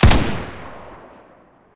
sound / weapons / machinegun